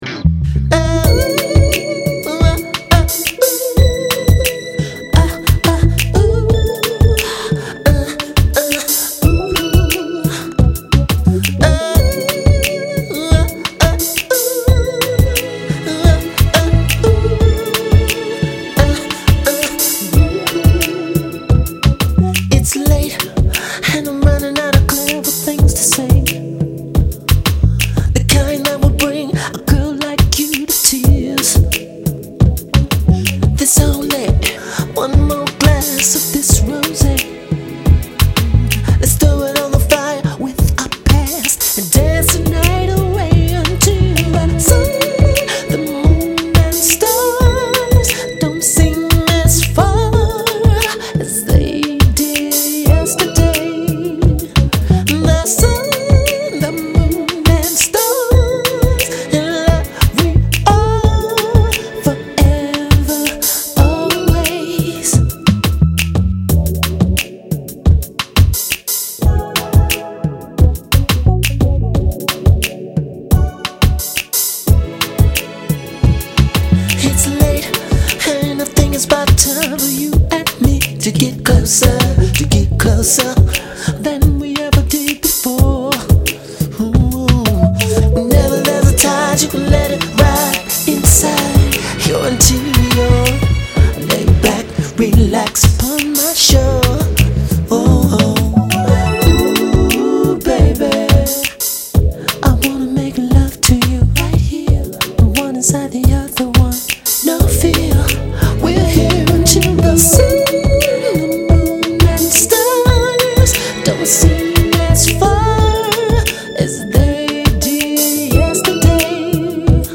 groovy R&B/soul songs